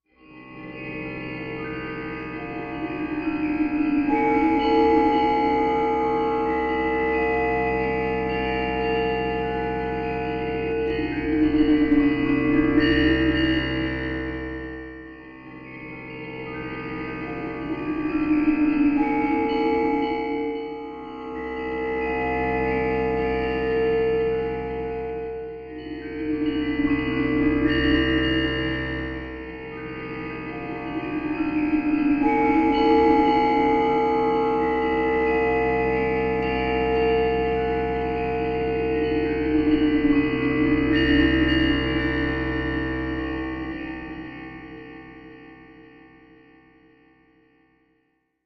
Almost Magic Metallic Moving Texture Bells Dark Wind